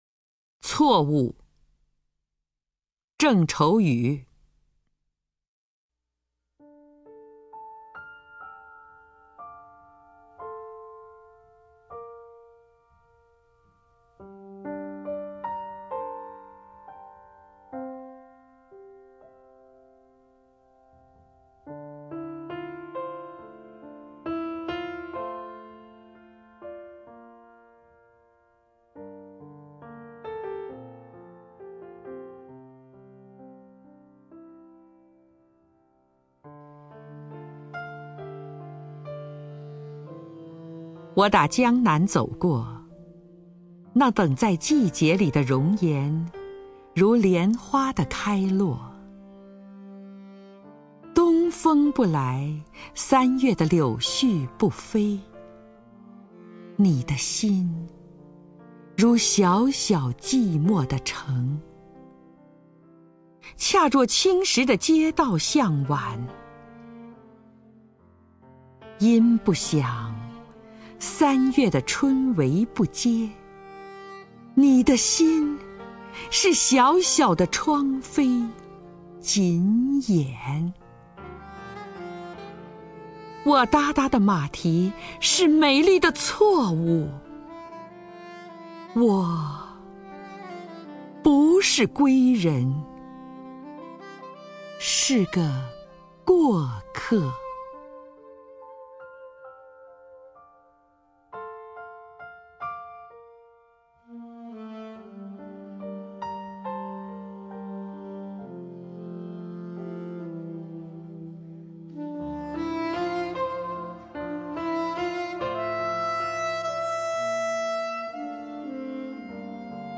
张筠英朗诵：《错误》(郑愁予)　/ 郑愁予
名家朗诵欣赏 张筠英 目录
CuoWu_ZhengChouYu(ZhangJunYing).mp3